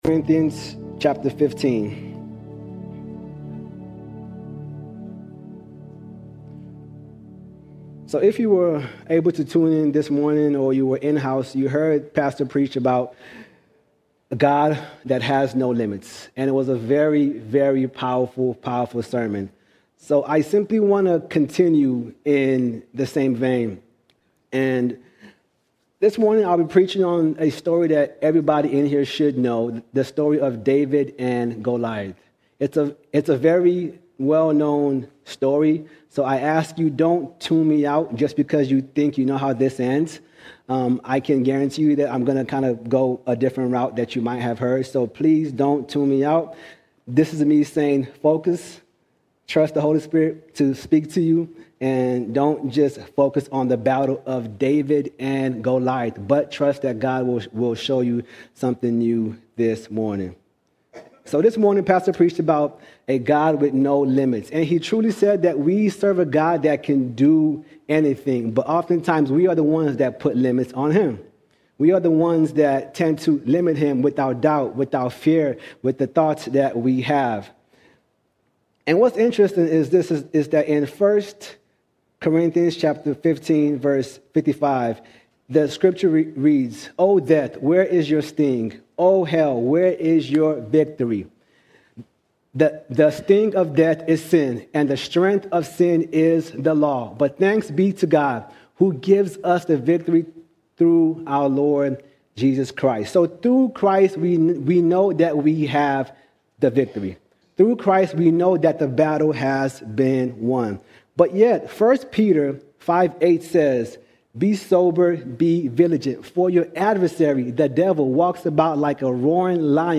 27 October 2025 Series: Sunday Sermons All Sermons The Battle Before The Battle The Battle Before The Battle We serve a God without limits!